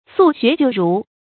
宿学旧儒 sù xué jiù rú
宿学旧儒发音